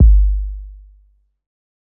Waka Kick 2 (7).wav